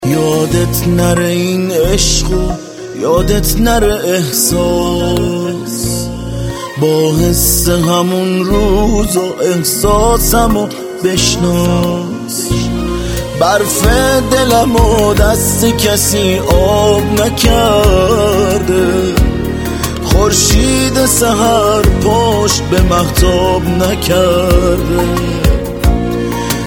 رینگتون ملایم و عاشقانه